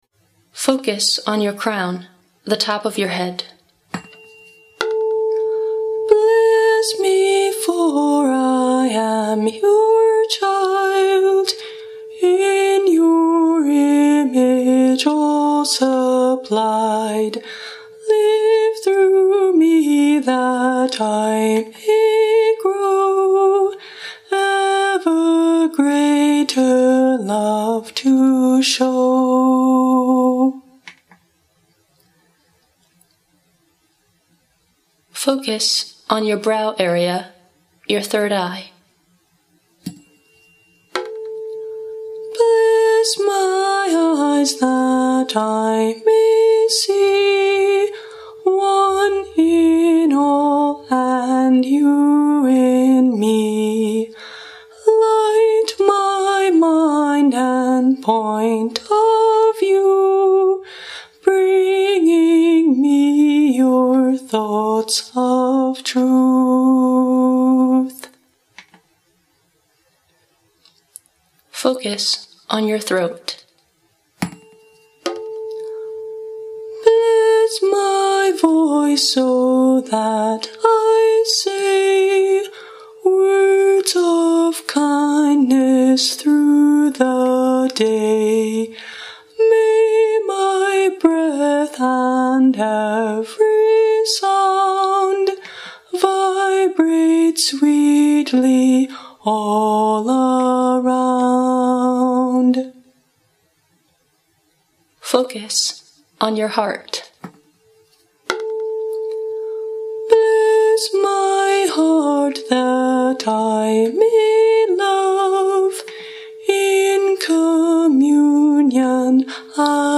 This is a self-blessing song, inspired by blessing and Chakra-cleansing rituals.
Instrument: A-440 (A4) tuning fork